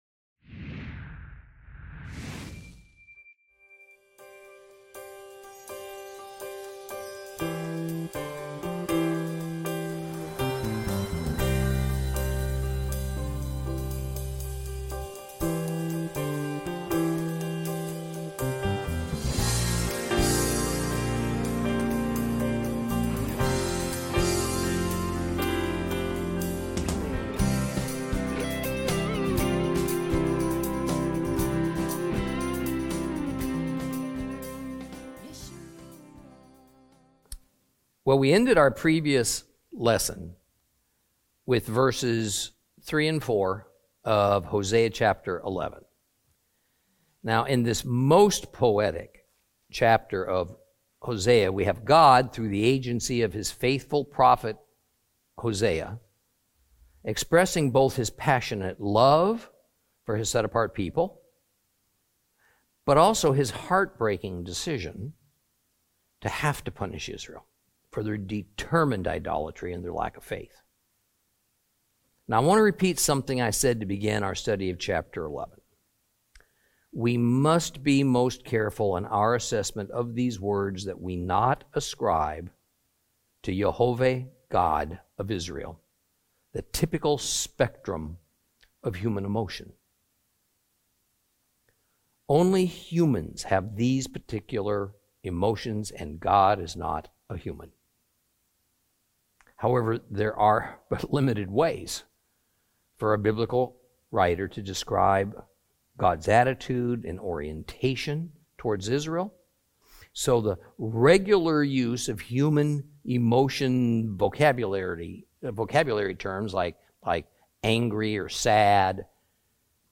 Teaching from the book of Hosea, Lesson 20 Chapters 11 and 12.